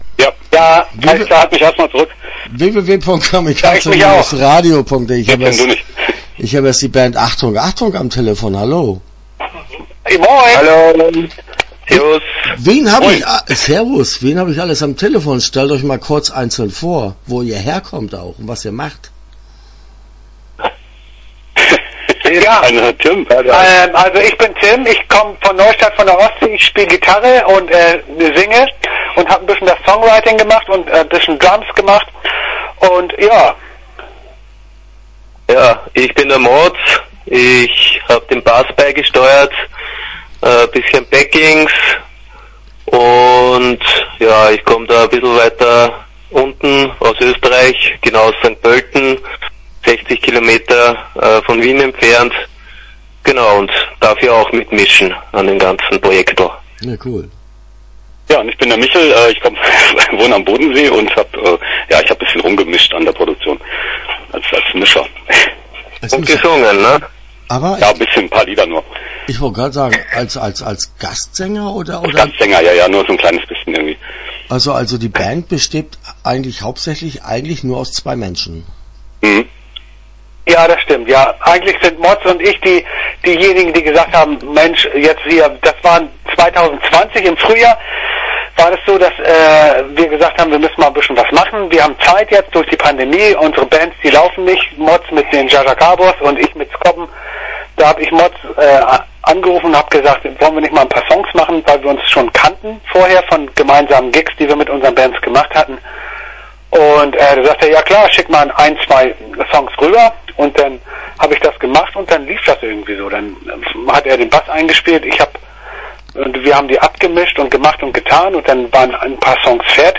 Achtung Achtung - Interview Teil 1 (12:31)